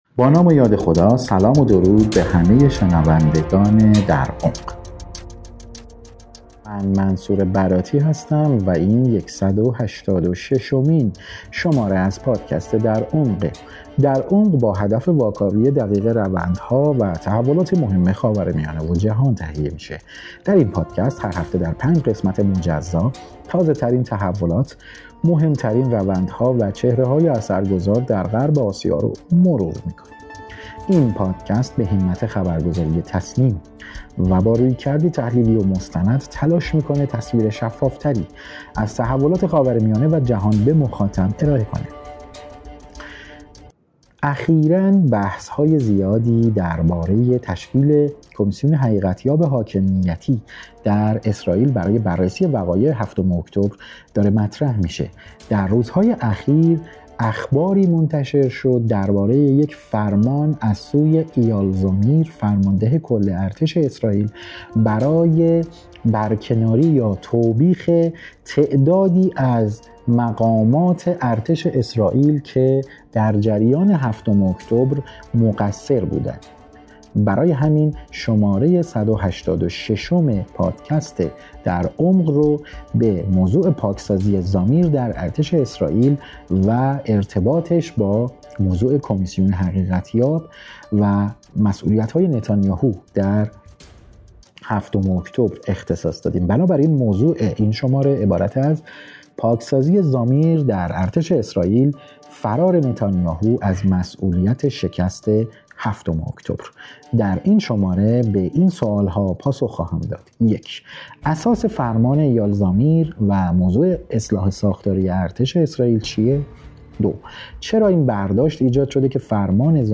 کارشناس مسائل رژیم صهیونیستی